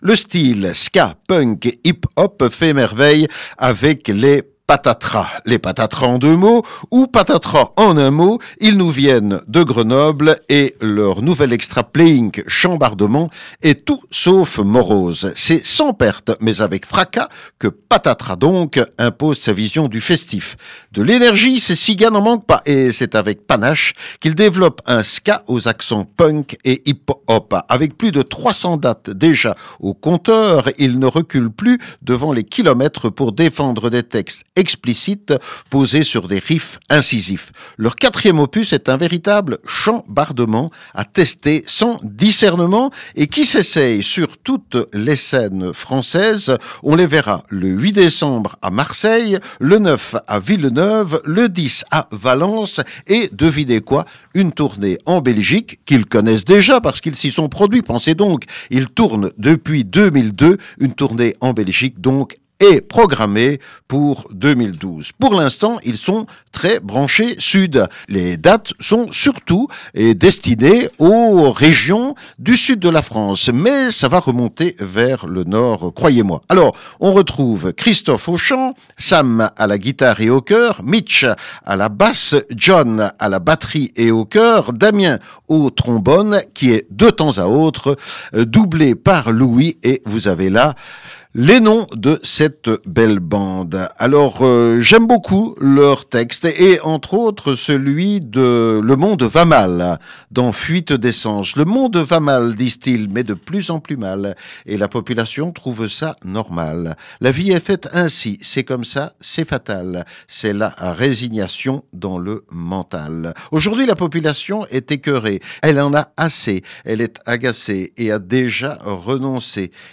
Explosif, fougueux, bouillonnant
Une formation rock dopée par la chaleur du reggae.